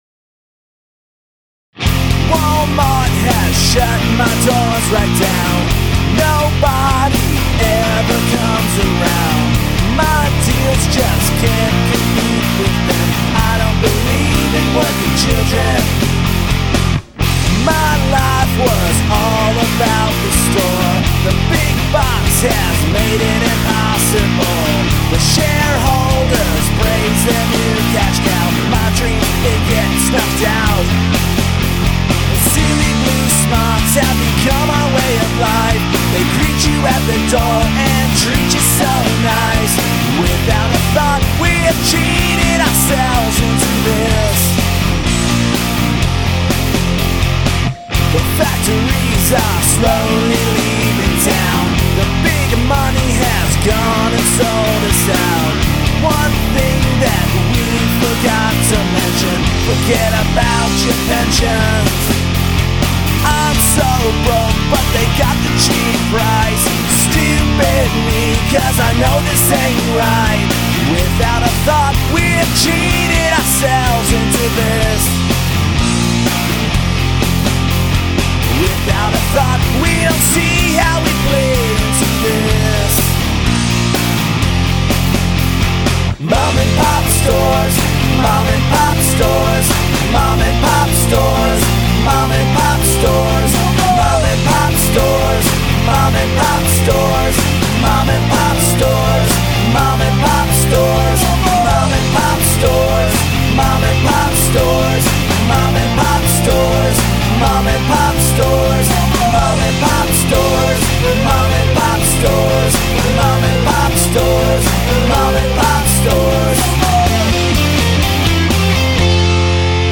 All instruments, vocals
* This is a collection of home demos/ first draft versions of songs written for fun. They are here in their most raw form, captured as ideas.